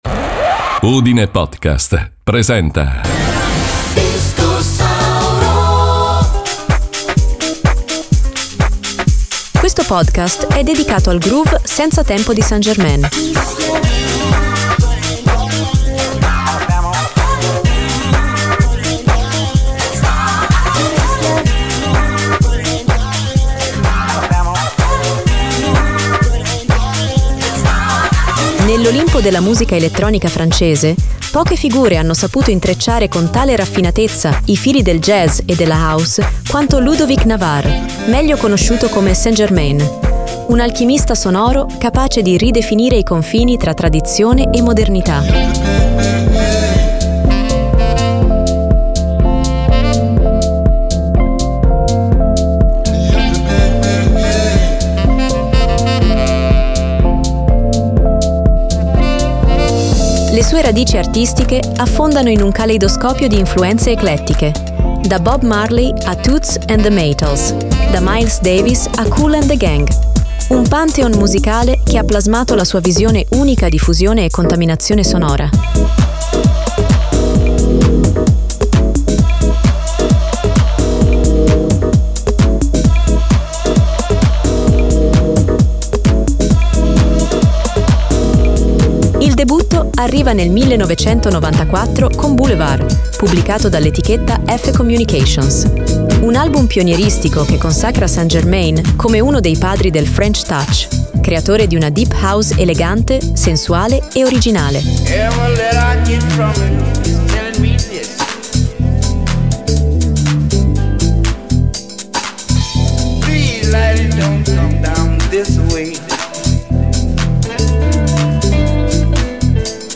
St Germain è tra i pochi produttori ad aver perseguito con coerenza la fusione autentica tra jazz e house, dando vita a un suono fatto di groove profondi, ritmi globali e improvvisazioni in perfetto equilibrio.